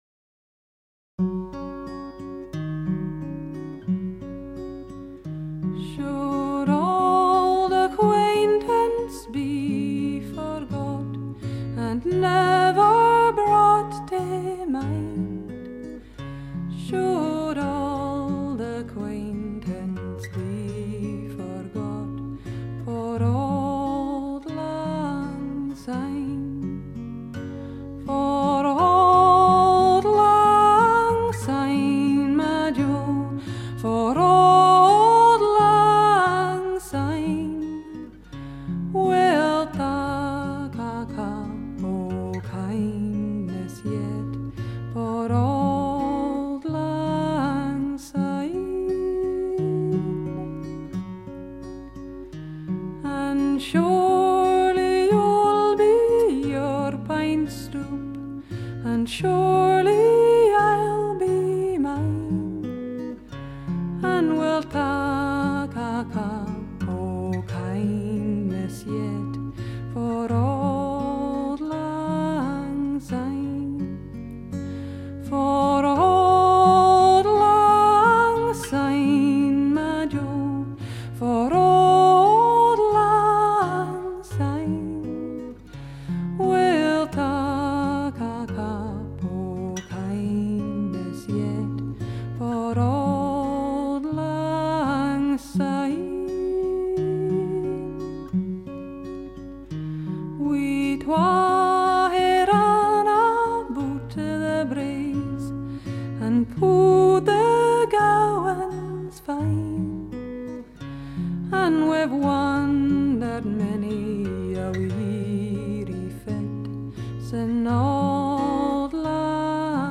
虽然只很简单的人声加吉他伴奏
本 片录音有着超乎寻常的丰满与充满细节